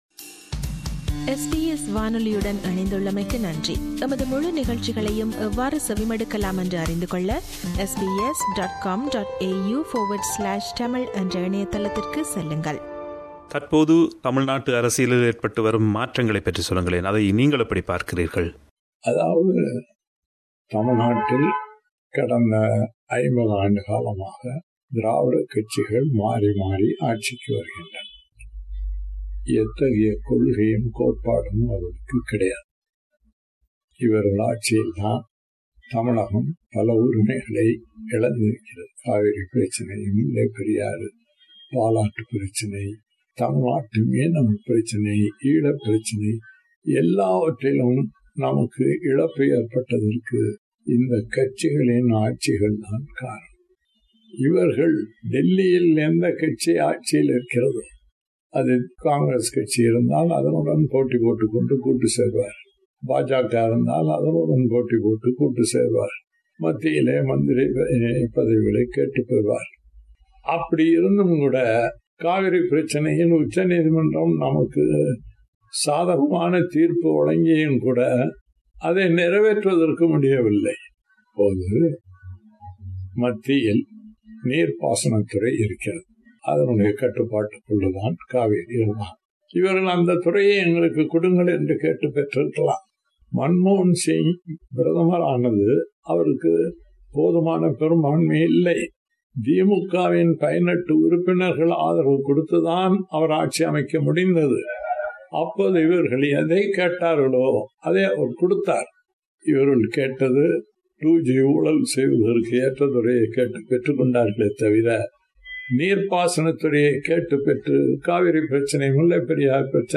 Pazha Nedumaran is a long standing, well respected, and an outspoken Tamil politician from the state of Tamil Nadu, South India.
interview